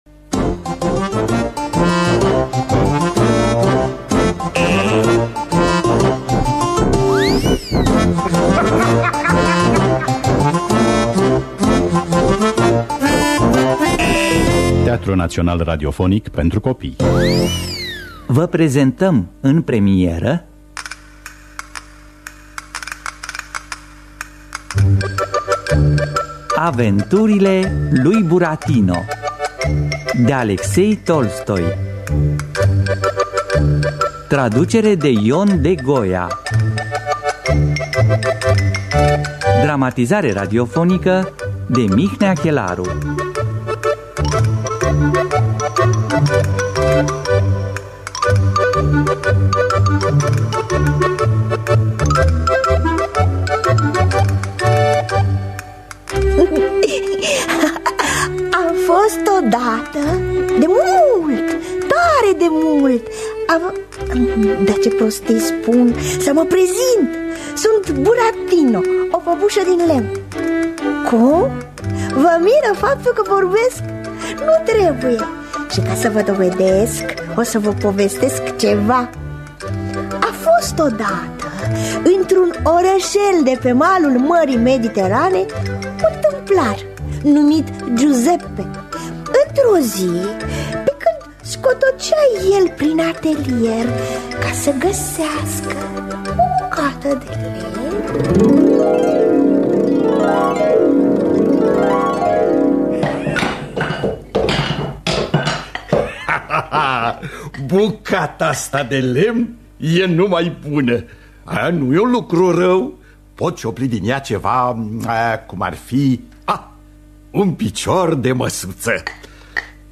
Aventurile lui Burattino sau Cheiţa de aur de Alexei Tolstoi – Teatru Radiofonic Online